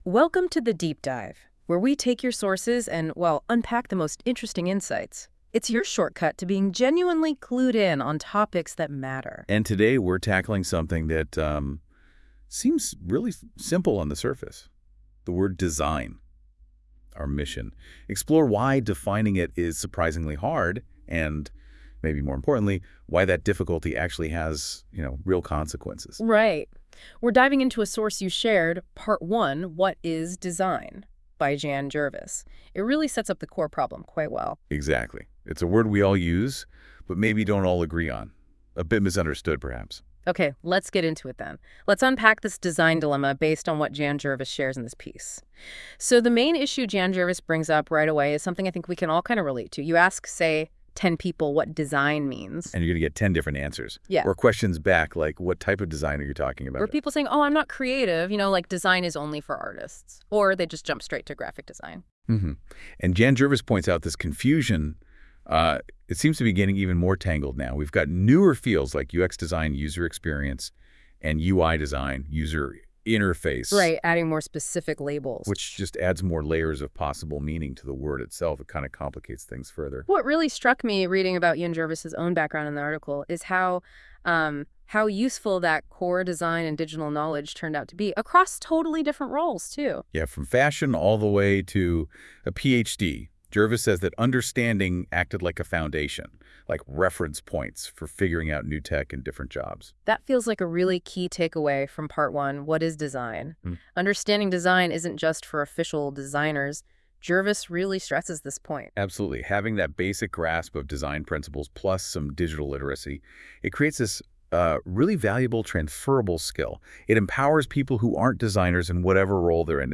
Listen to this AI-hosted version of my (human-written) blog post below! It is quite fascinating how the two AI hosts interact with the content.